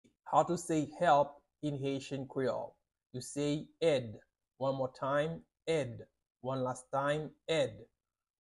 How to say "Help" in Haitian Creole - "Èd" pronunciation by a native Haitian tutor
“Èd” Pronunciation in Haitian Creole by a native Haitian can be heard in the audio here or in the video below:
How-to-say-Help-in-Haitian-Creole-Ed-pronunciation-by-a-native-Haitian-tutor.mp3